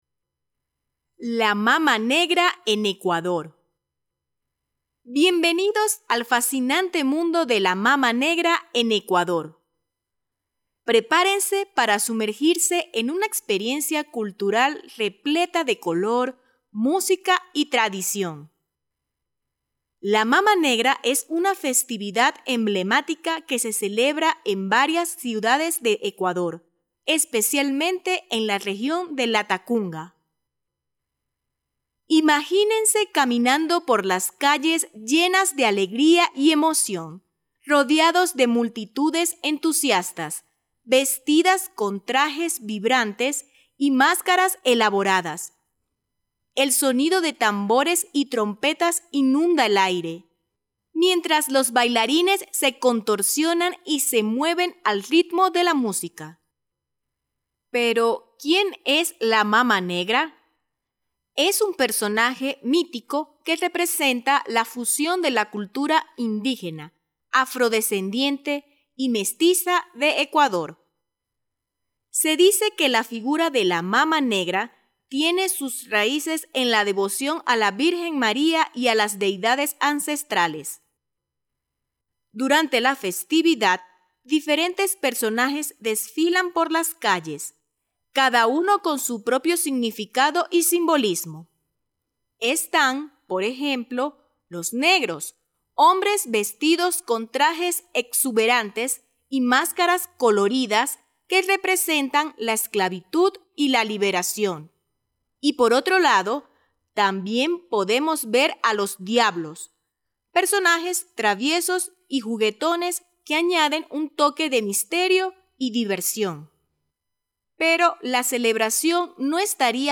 Spanish online reading and listening practice – level B1
audio by a Latin American voice professional.